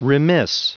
Prononciation du mot remiss en anglais (fichier audio)
Prononciation du mot : remiss